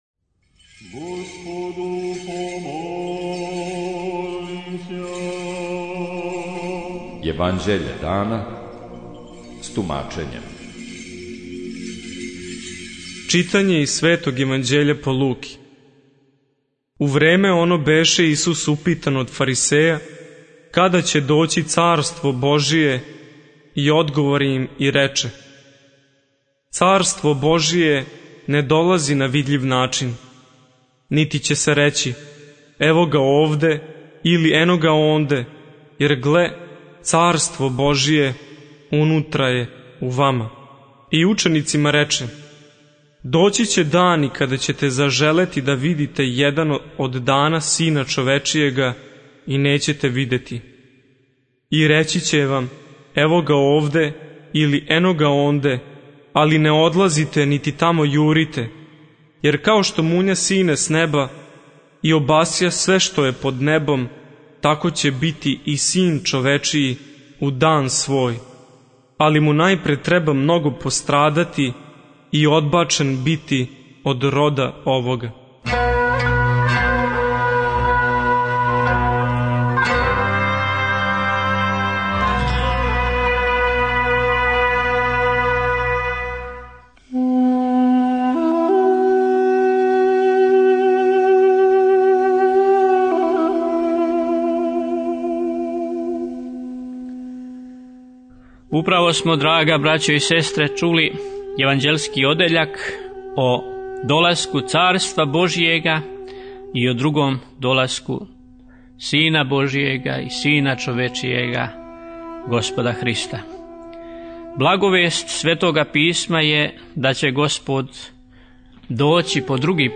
Читање Светог Јеванђеља по Матеју за дан 30.06.2024. Зачало 38.